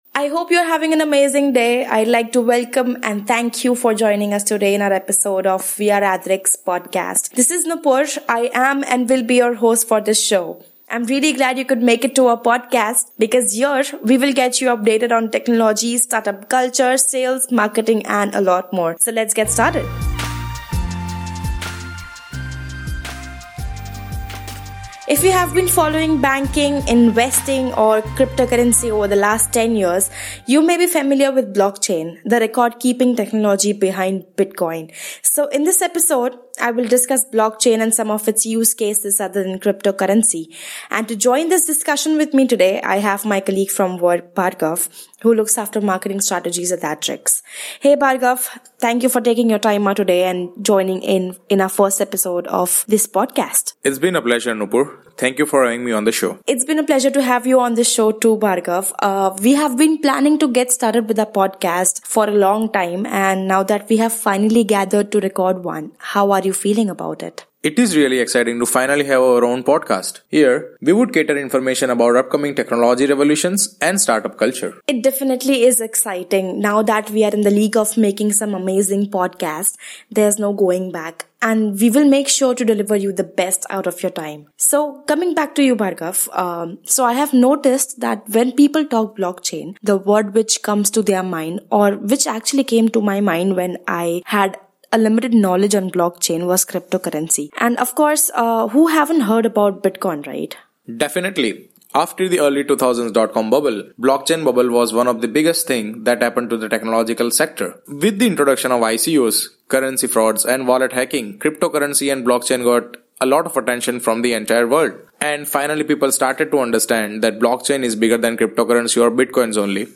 In this interview, they are discussing how Blockchain is not limited to cryptocurrency and can be used in multiple industries to bring a positive shift in the market.